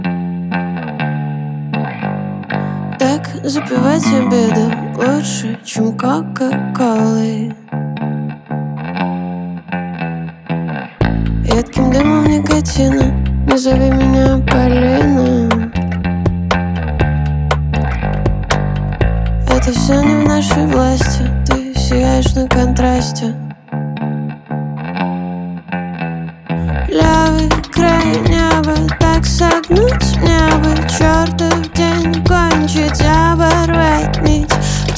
Жанр: Иностранный рок / Рок / Инди / Русские